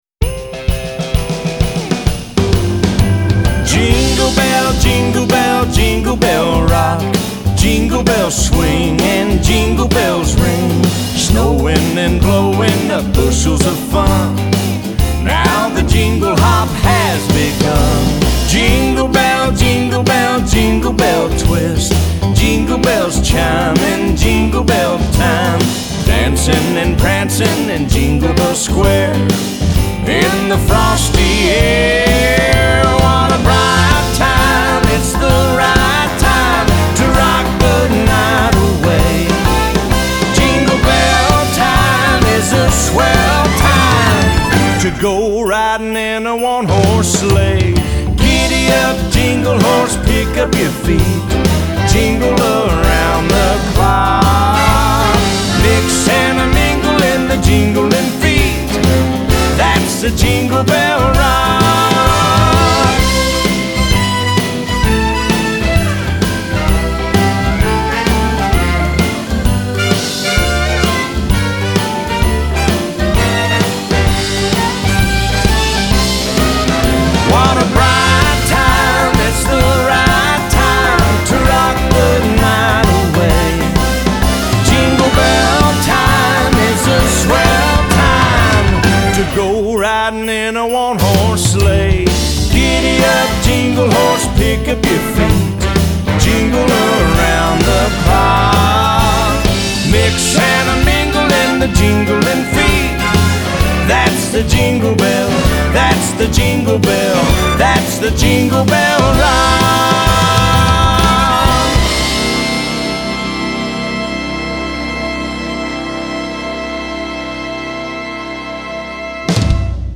Genre : Christmas Music